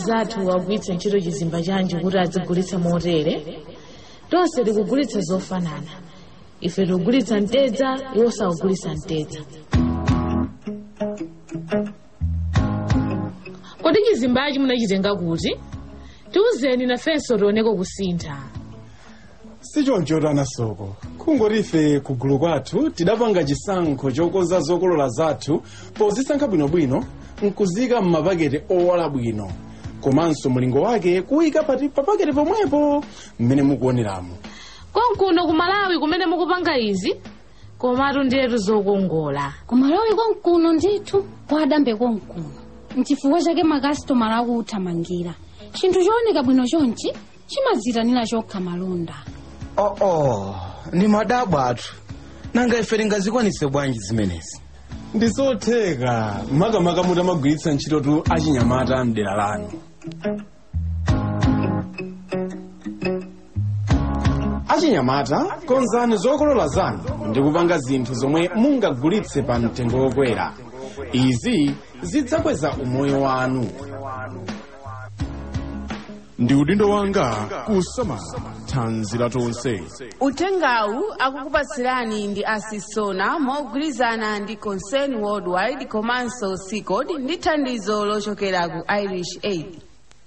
Advert: Add Value To Your Produce